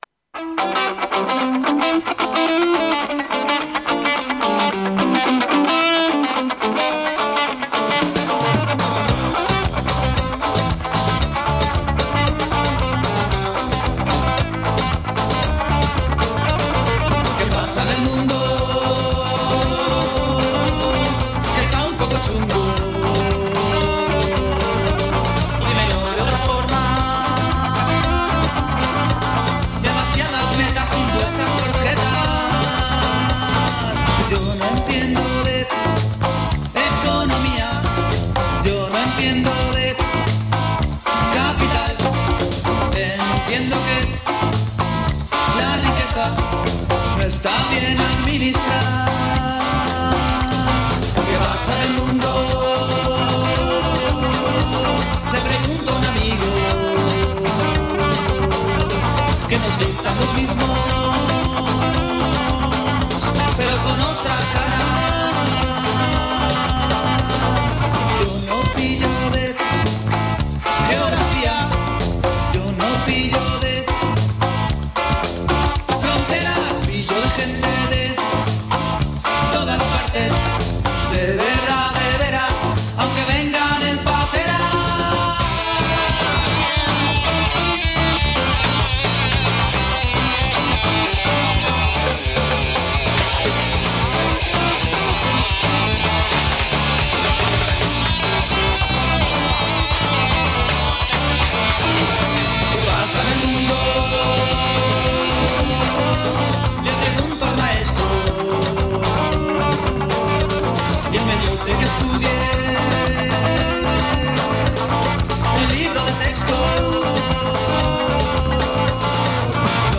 Tertuliando sobre la represión policial.
Extracto de entrevista a Alexis Tsipras, líder de Syriza.